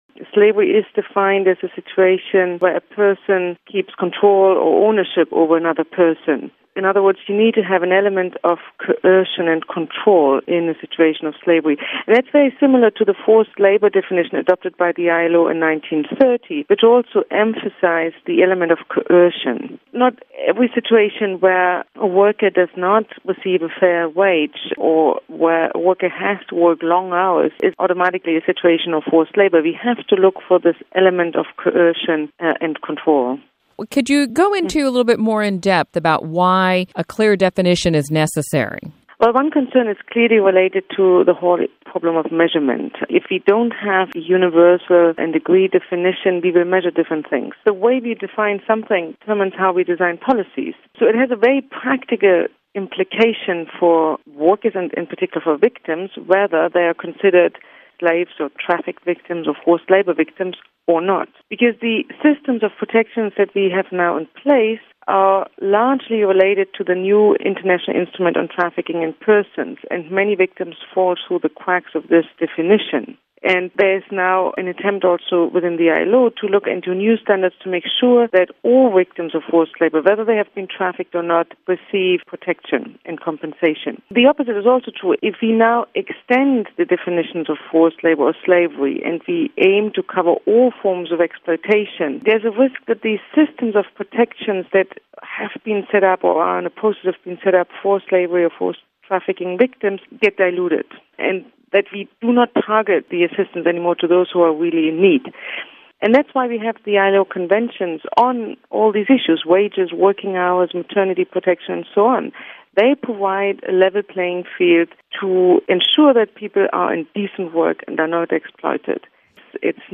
Q&A: When is a Slave a Slave?